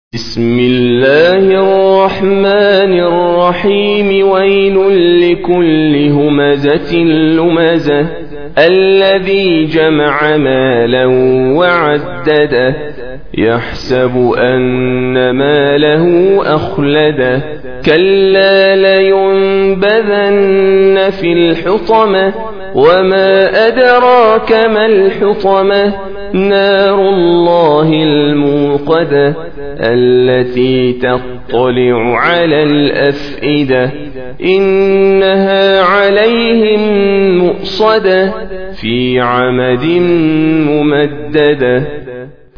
104. Surah Al-Humazah سورة الهمزة Audio Quran Tarteel Recitation
حفص عن عاصم Hafs for Assem